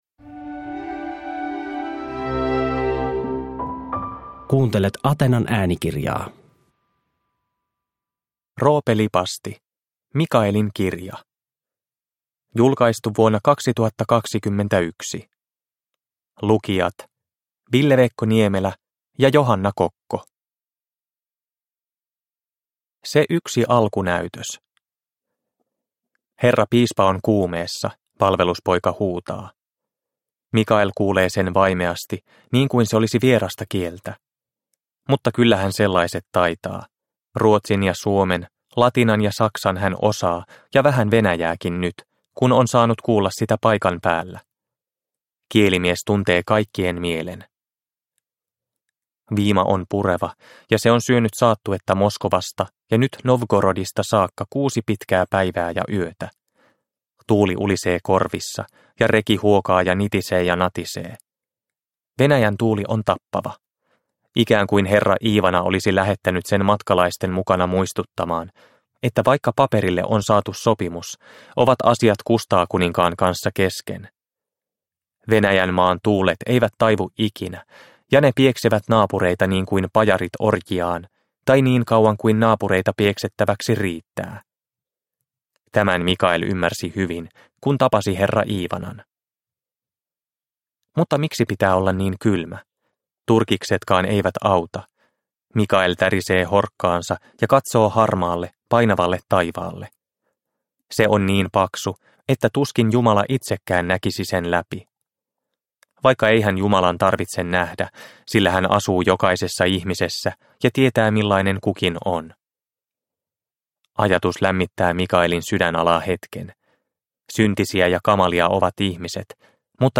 Mikaelin kirja – Ljudbok – Laddas ner